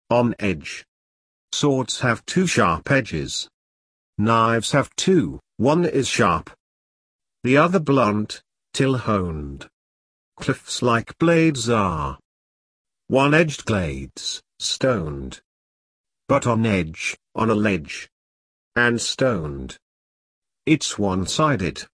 Form: Free verse